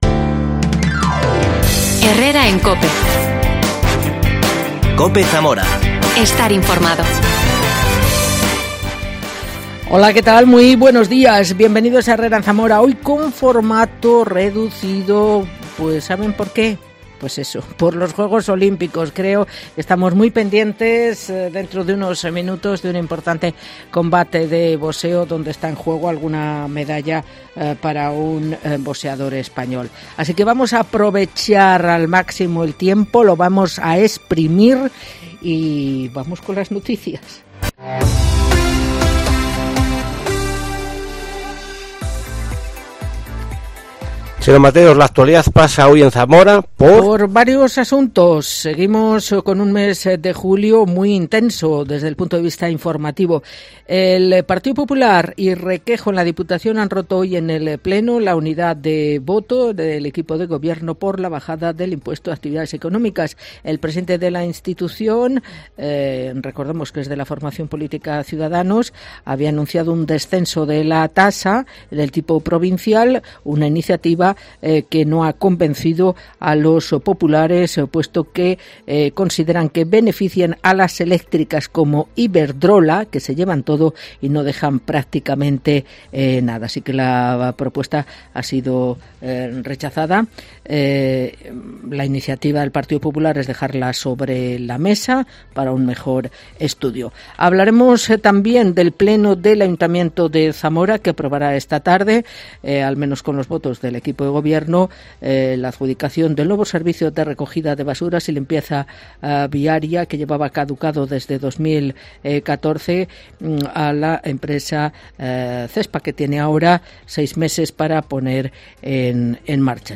AUDIO: Entrevista al alcalde de Toro, Tomás del Bien